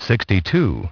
Transcription and pronunciation of the word "sixty-two" in British and American variants.